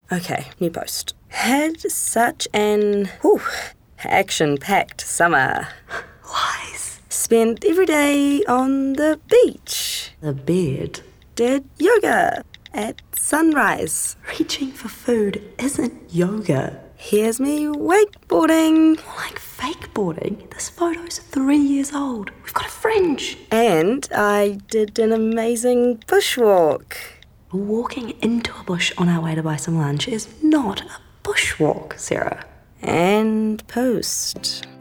Demo
Young Adult, Adult
australian | natural
new zealand | natural
warm/friendly